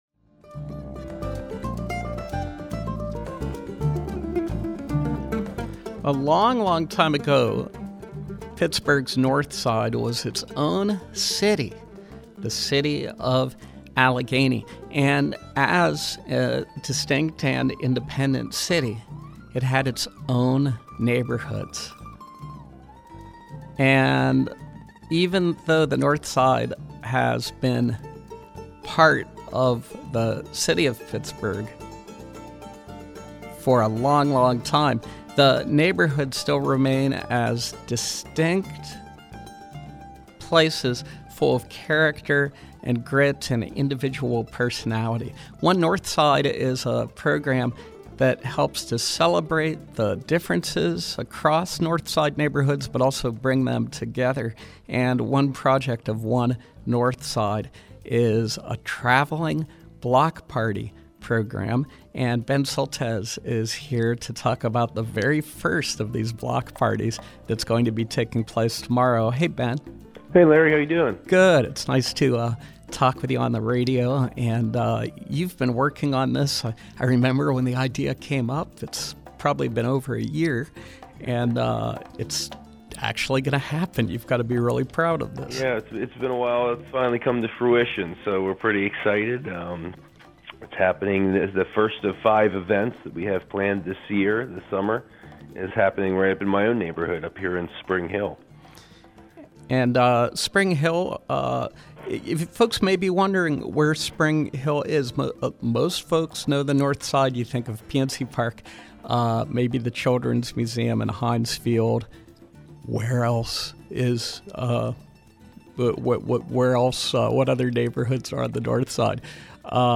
Interview: One Northside Block Party, Spring Hill